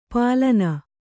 ♪ pālana